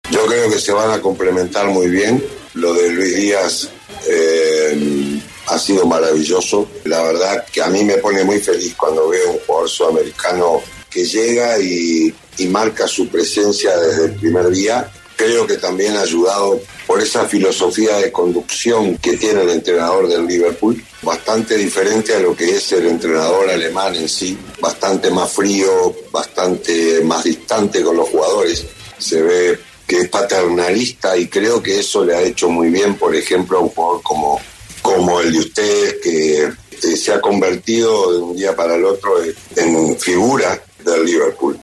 (Luis Díaz en entrevista con El Alargue de Caracol Radio)